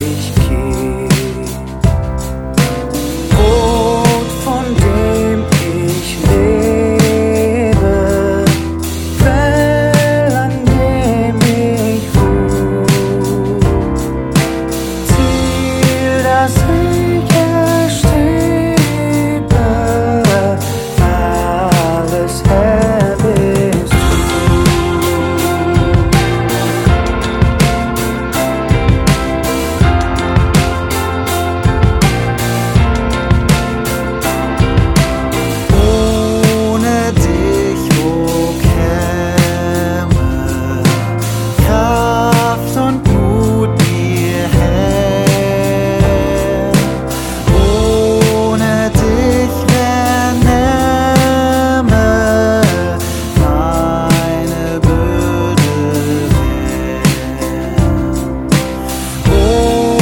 • Sachgebiet: Pop